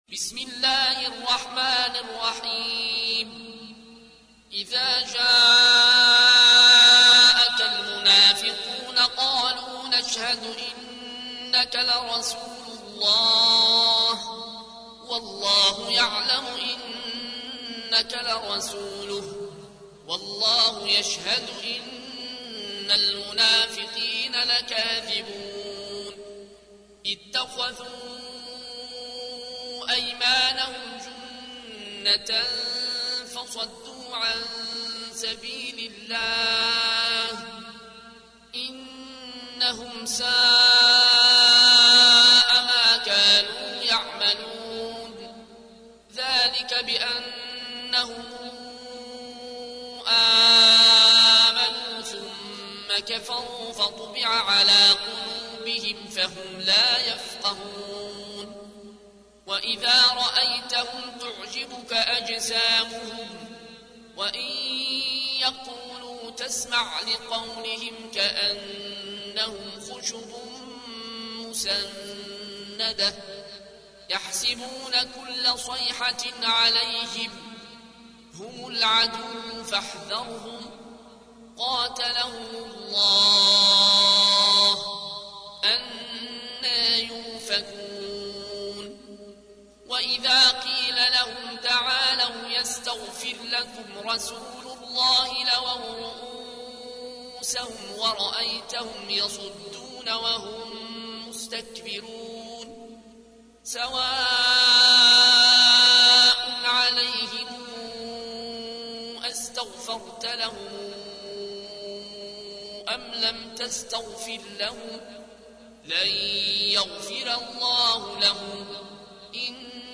تحميل : 63. سورة المنافقون / القارئ العيون الكوشي / القرآن الكريم / موقع يا حسين